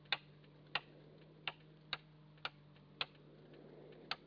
The quality is kept to a minimum because of webspace limitations.